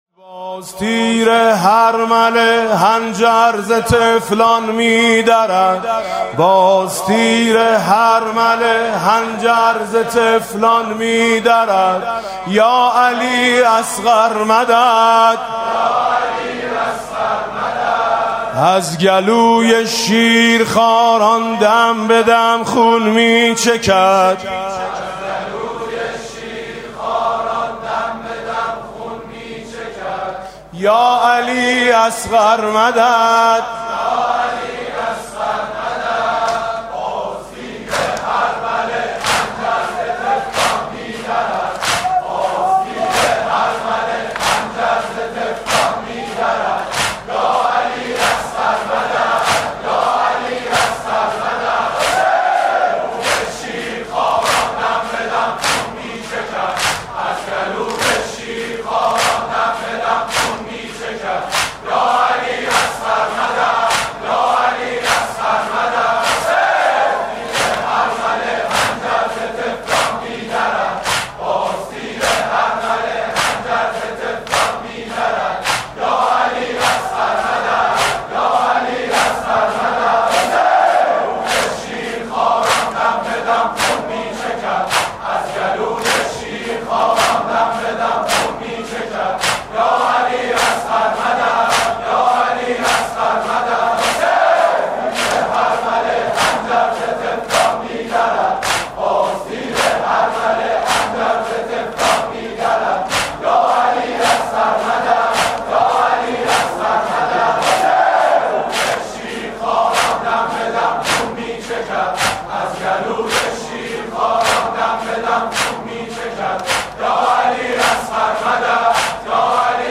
دودمه